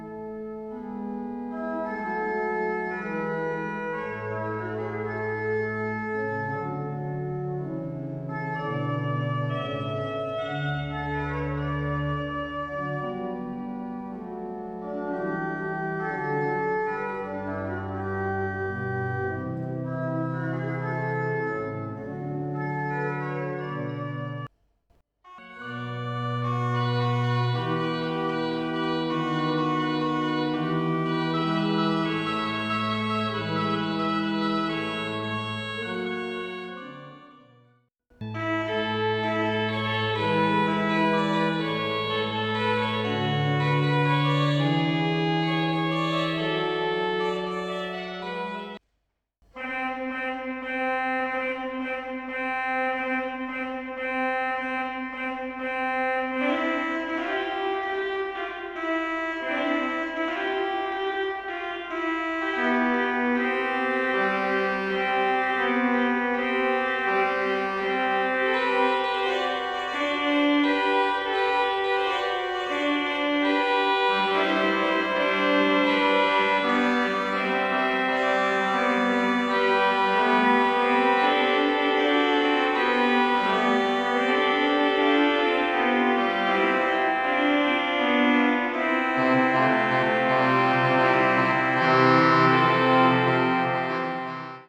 in Ergänzung zu meinen Urlaubsorgeln nur für begrenzte Zeit ein paar Klänge der Orgel in Briones (ca. 1 1/2 Minuten). Vorsicht: Das ist keine "Kunst", alles aus dem Stand an ungewohnter und unergonomischer Orgel, also nicht geübt:
Zum drin-Baden!
...beim nochmal reinhören über mein LD Dave8 wird klar, dass das die Horizontalzungen gewesen sein müssen.
Briones_Zungen.wav